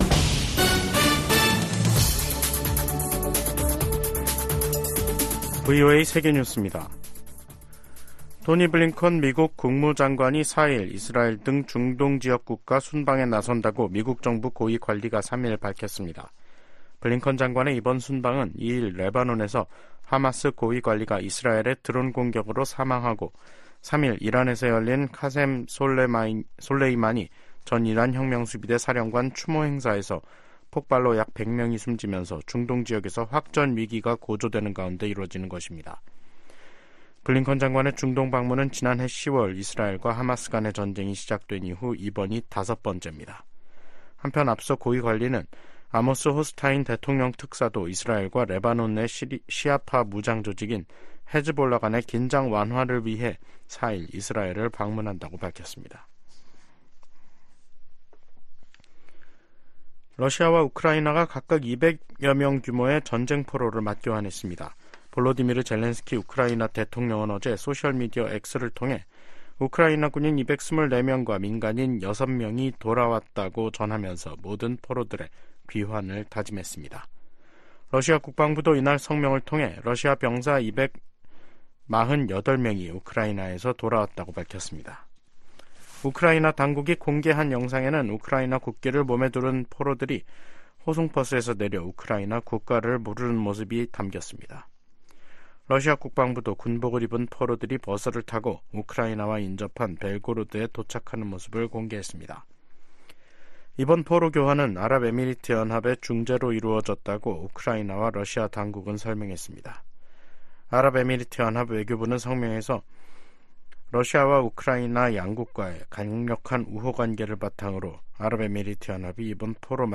VOA 한국어 간판 뉴스 프로그램 '뉴스 투데이', 2024년 1월 4일 3부 방송입니다. 미국은 북한과의 대화에 큰 기대는 않지만 여전히 환영할 것이라고 국무부 대변인이 말했습니다. 김정은 북한 국무위원장이 남북관계를 '적대적 두 국가 관계'로 선언한 이후 북한은 대남노선의 전환을 시사하는 조치들에 나섰습니다. 23일로 예정된 중국에 대한 유엔의 보편적 정례인권검토(UPR)를 앞두고 탈북민 강제북송 중단 압박이 커지고 있습니다.